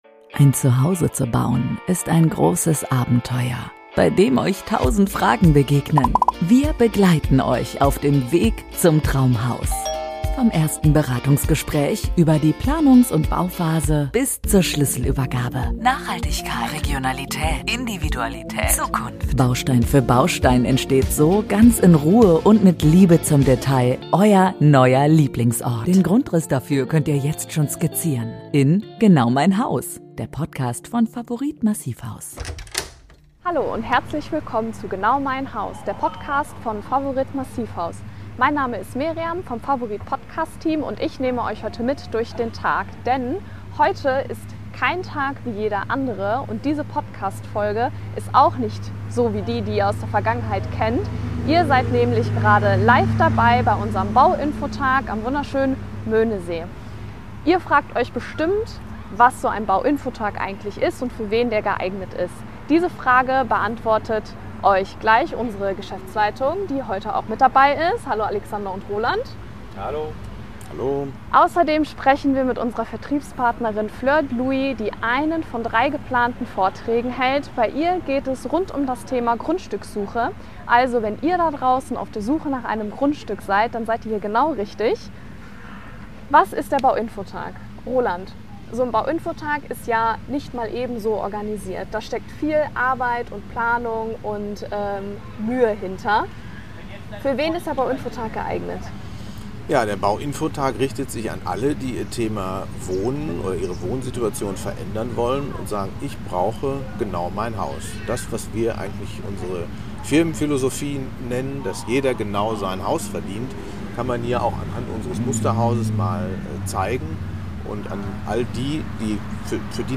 Folge #17 - LIVE vom Bau-Infotag ~ GENAU MEIN HAUS Podcast
Beschreibung vor 1 Jahr Willkommen zu einer besonderen Ausgabe unseres Podcasts GENAU MEIN HAUS: Wir nehmen euch LIVE mit auf den Bau-Infotag von Favorit Massivhaus.